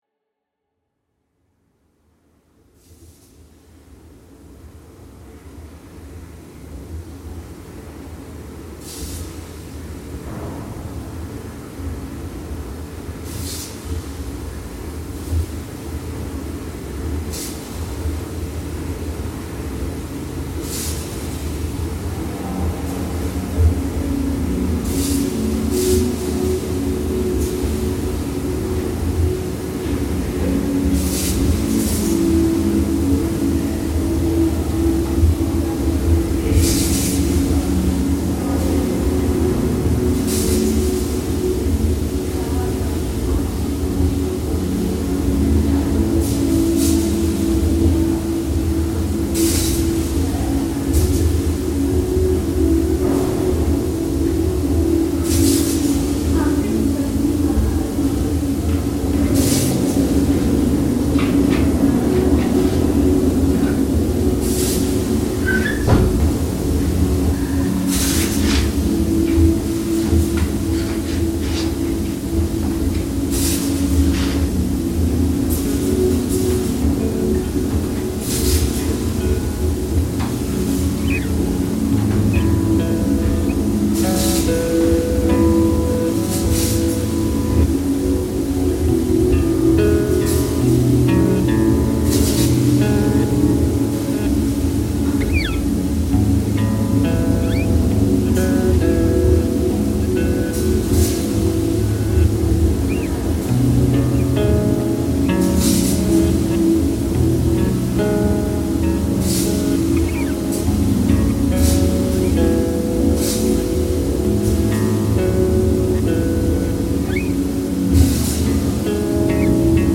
Szechenyi Baths steam room reimagined